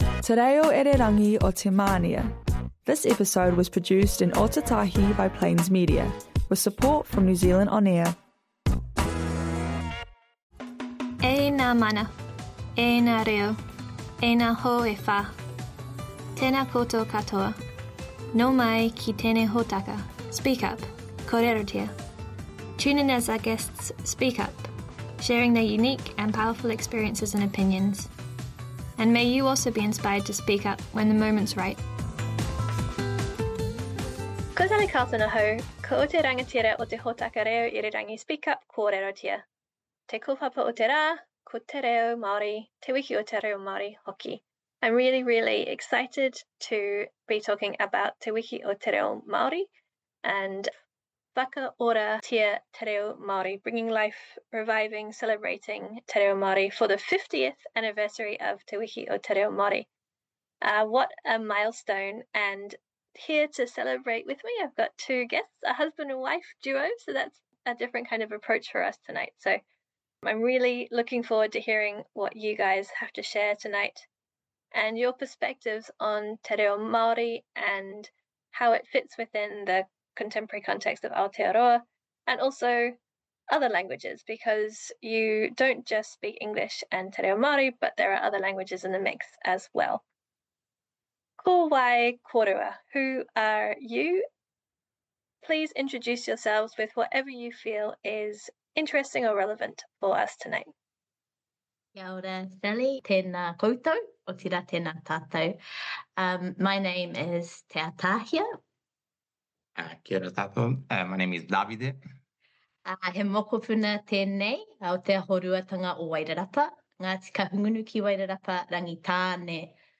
Episode of human rights radio show, Speak up - Kōrerotia, about learning and giving life to te reo in a whānau environment.